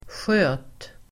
Uttal: [sjö:t]